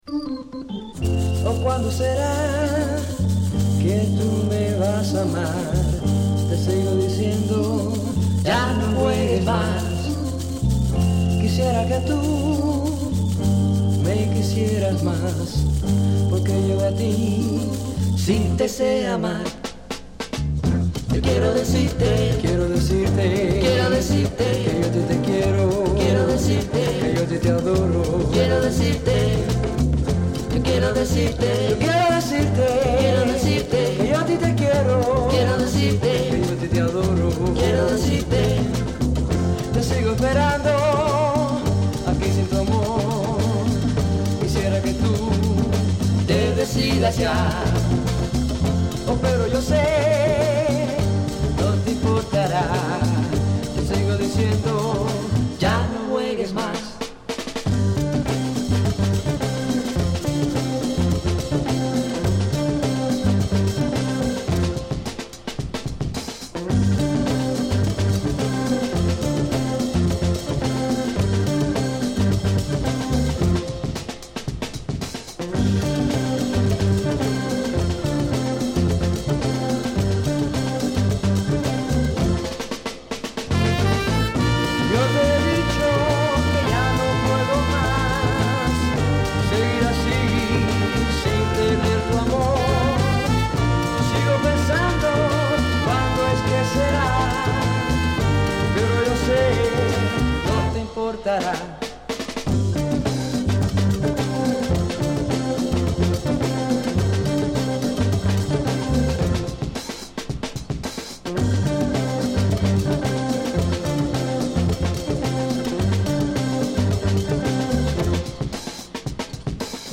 straight up Latin heat